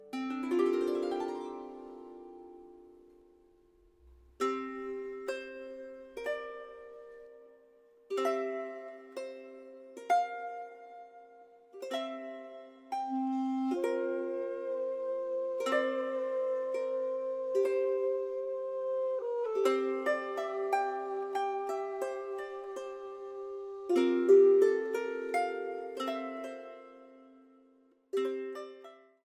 古楽器たちが踊り出す。
心地よい古楽器のアンサンブルをお楽しみ下さい。